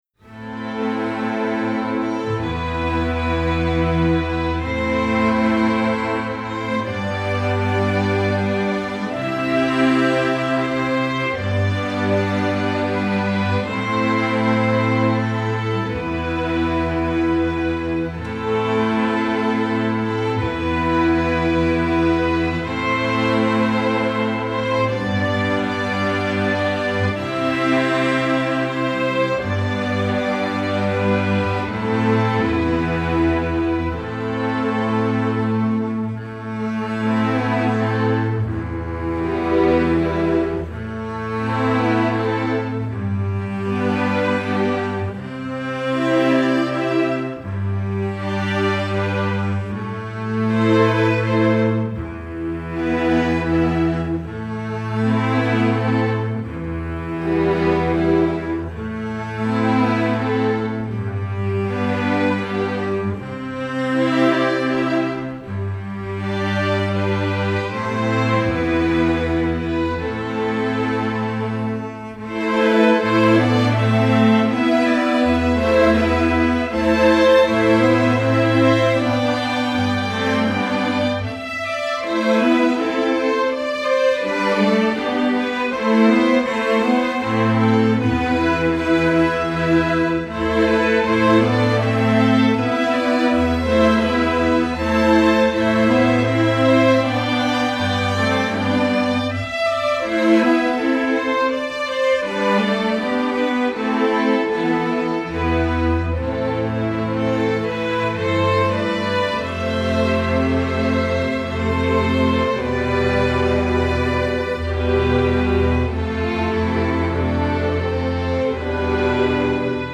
Instrumentation: string orchestra
classical